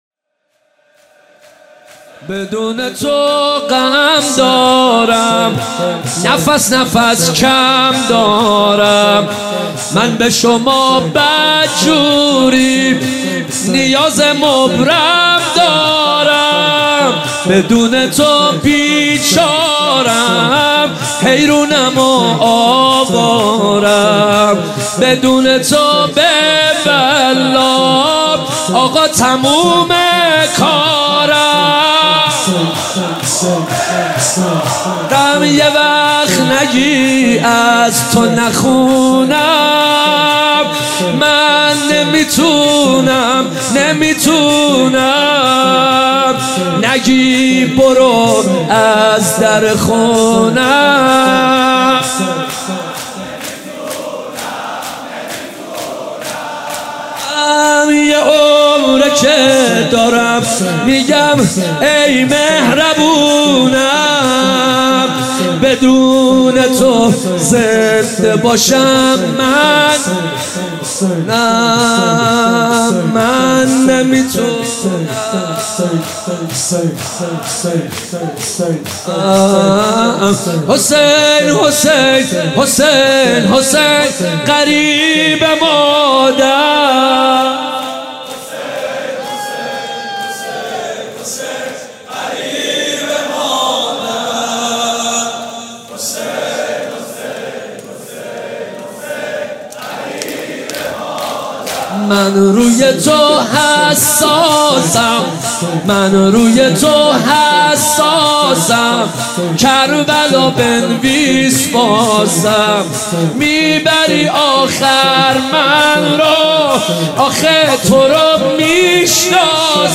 هیات مکتب الحسین اصفهان
بدون تو غم دارم | شور | حضرت امام حسین علیه السلام